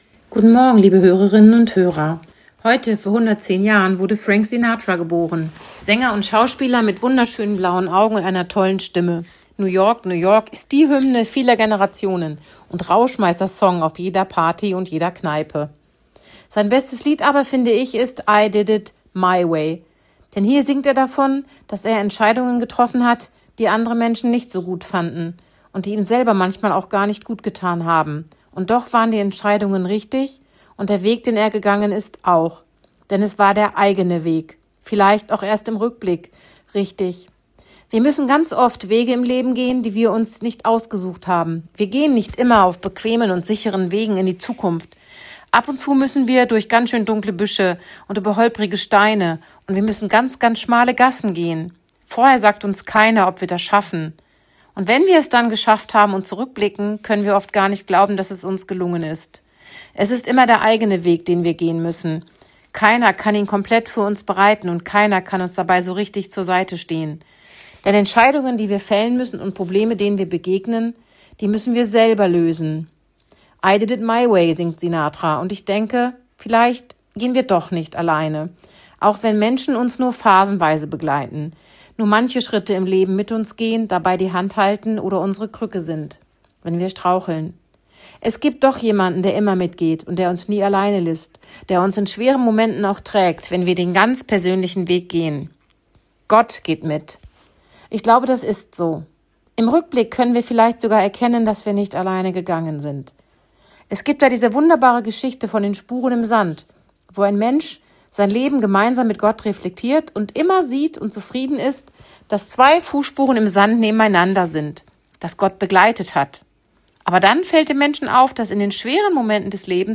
Radioandacht vom 12. Dezember